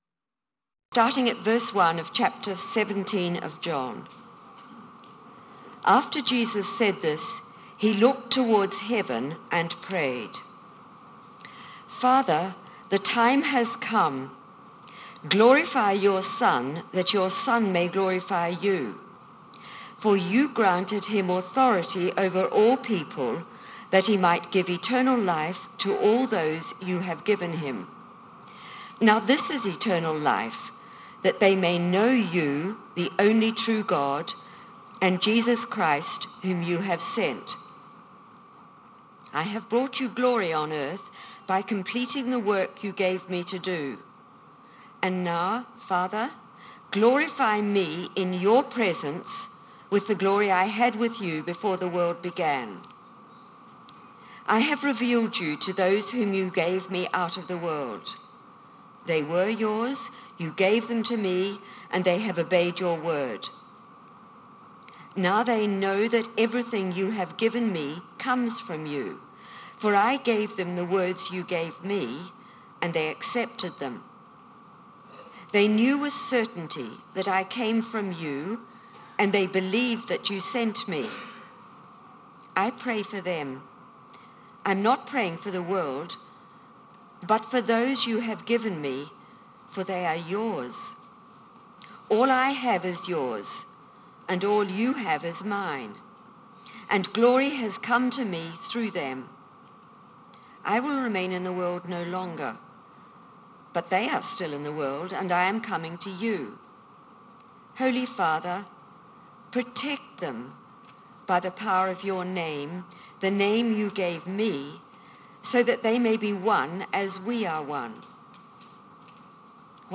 Media Library Media for Sunday Service on Sun 08th Jan 2023 10:00 Speaker
Whose mission is it anyway? Sermon